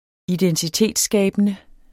Udtale [ -ˌsgæˀbənə ]